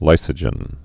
(līsə-jən)